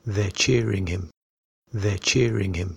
The pronunciation of /ʤ/ and /ʧ/
U1_T3_cheering_jeering2.mp3